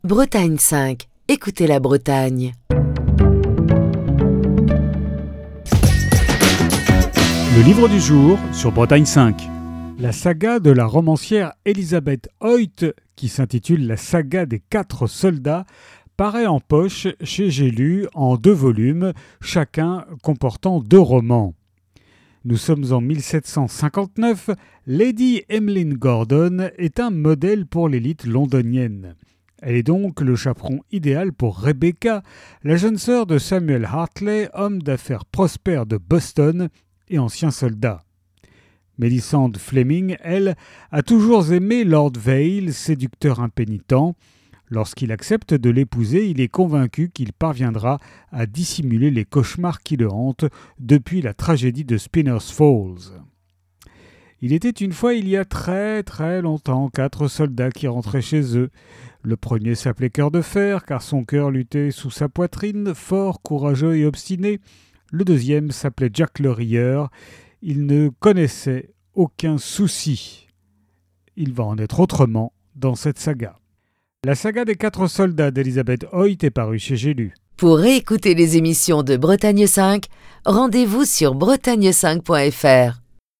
Chronique du 24 juin 2025.